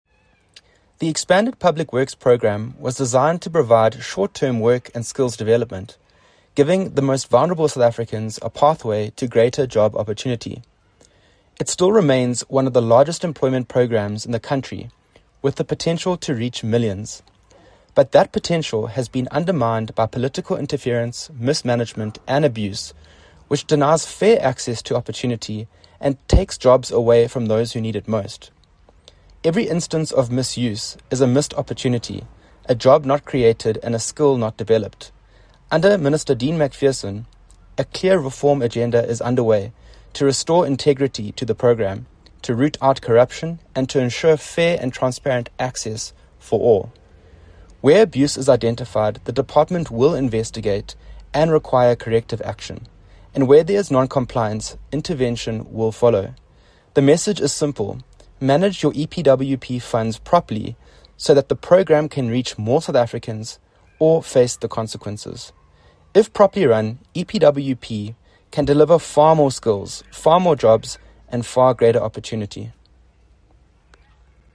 Soundbite by Edwin Macrae Bath MP.